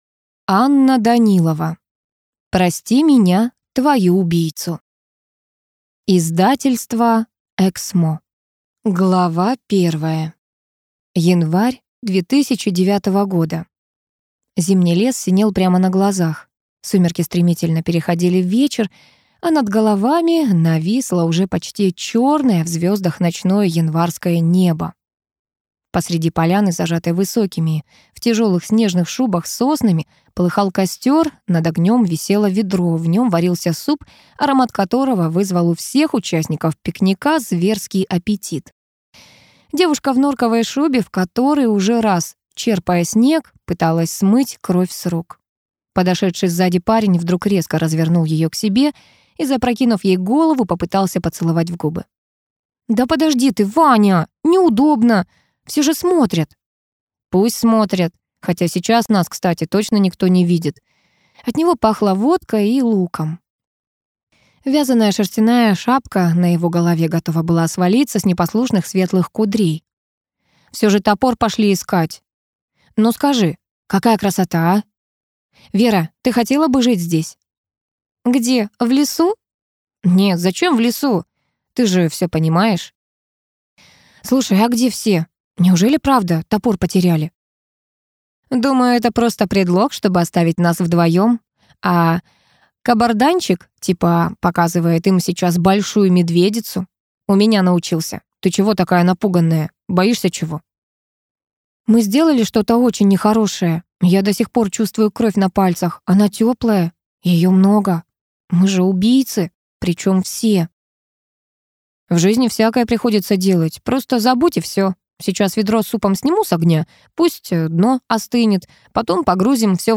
Аудиокнига Прости меня, твою убийцу | Библиотека аудиокниг
Прослушать и бесплатно скачать фрагмент аудиокниги